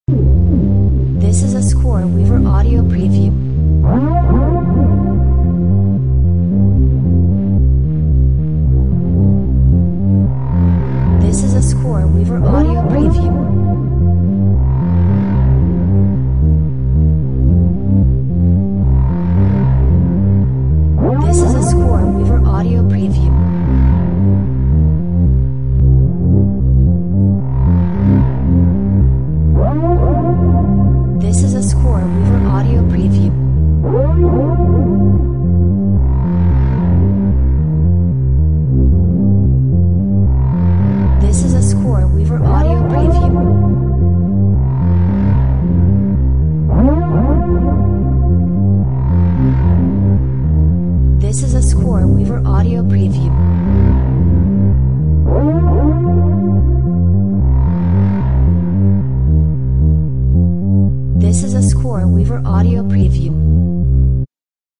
It’s hypnotic.